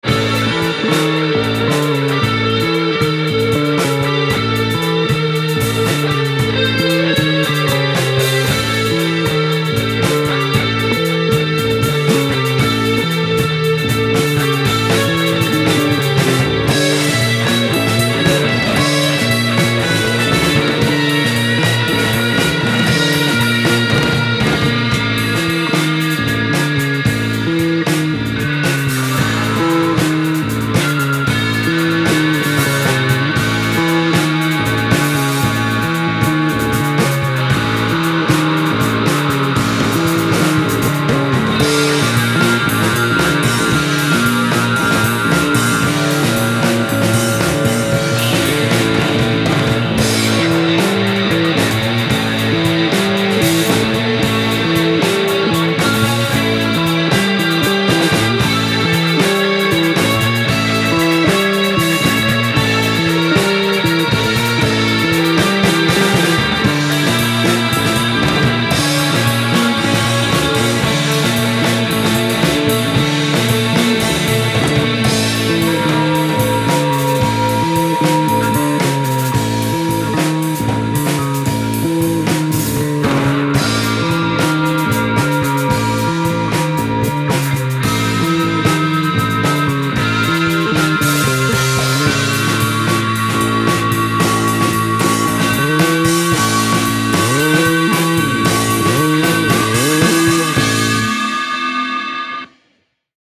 En esta sección GRAVELAND encontraréis periódicamente grabaciones en directo, en baja calidad (grabados en cassete con un micro colgado de un palo), con comentarios banales pisando inicios y finales de algunos cortes, discusiones sobre paridas insólitas, batallas de volúmenes, y bromas y referencias personales que a veces ni siquiera entendemos nosotros al hacer la reaudición.
Gran parte de estas grabaciones son improvisaciones a partir de la nada, de una nota accidental al tocar las cuerdas o dejar caer las baquetas, o de un ritmo autodisparado por algún pedal.